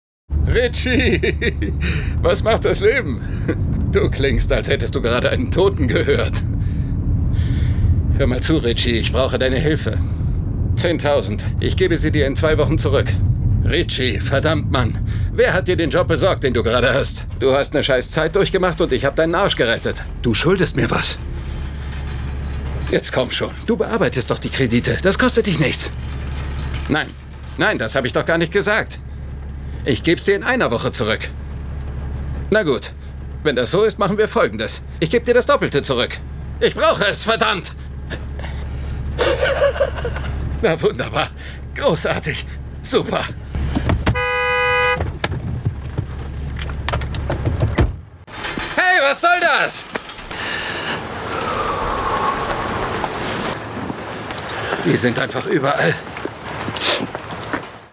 Mittel plus (35-65)
Lip-Sync (Synchron)